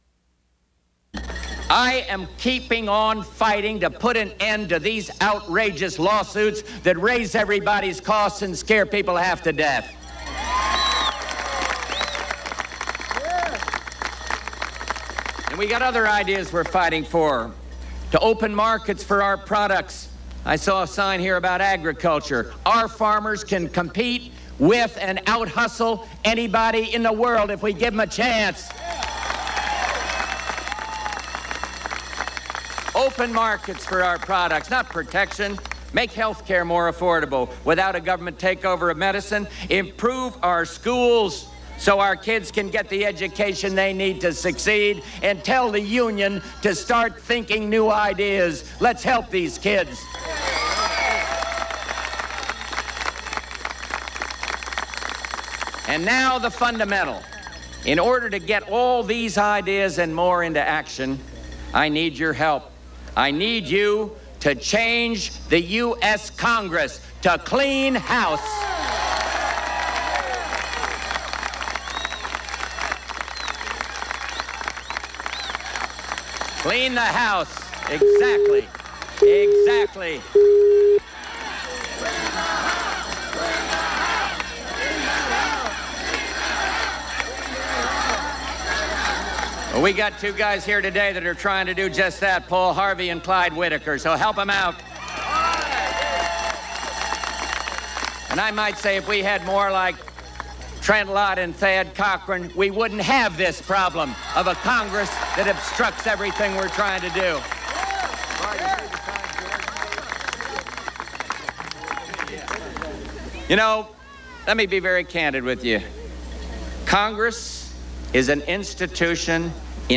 President Bush talks in Gulfport, Mississippi the day after accepting the Republican nomination for president